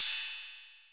Ride SwaggedOut.wav